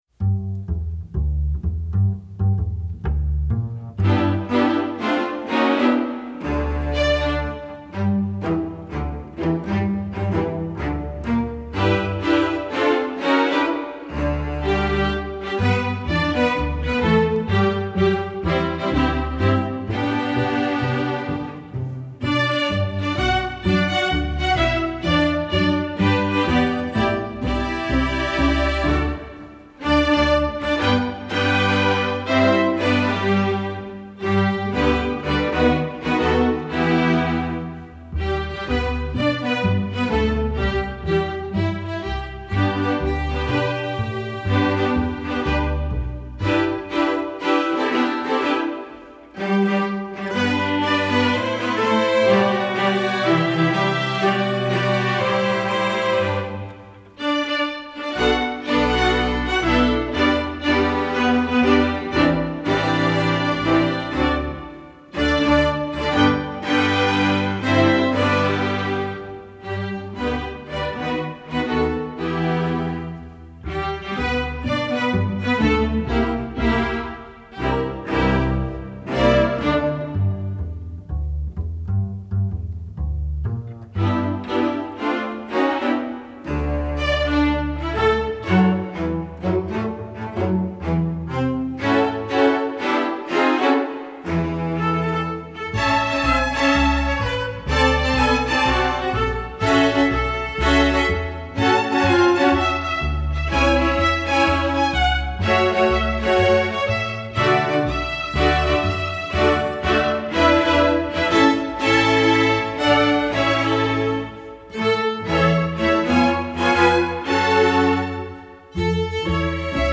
The African-American spiritual
String Orchestra